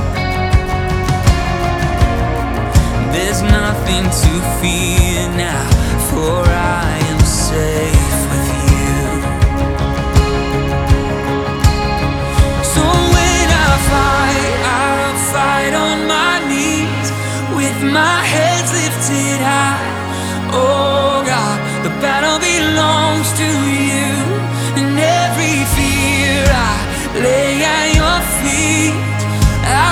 • Christian & Gospel